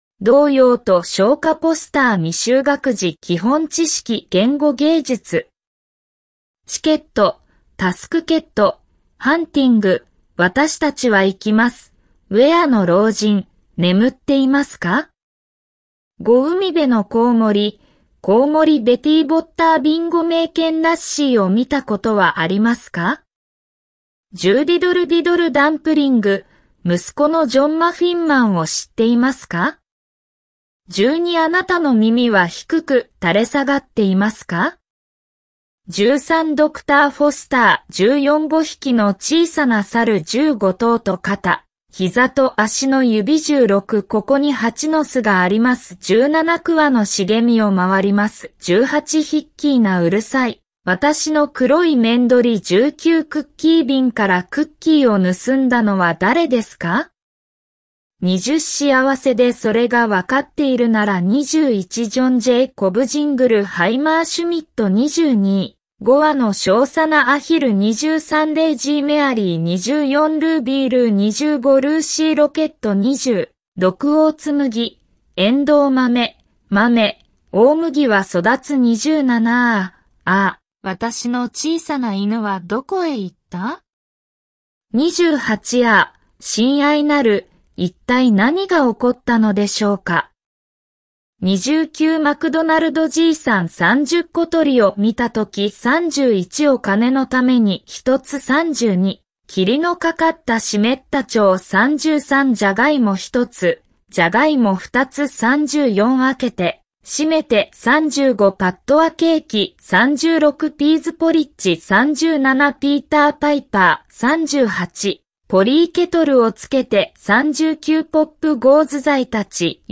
CKF 童謡と童謡, CKF童谣和歌曲
123_PK_BB_NurseryRhymesSongs-CKF-FKB_ja.mp3